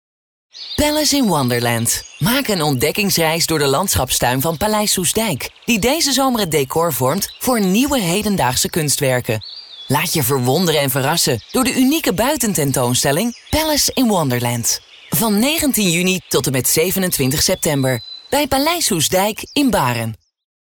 Grabaciones en nuestro estudio de sonido asociado de Holanda.
Locutoras holandesas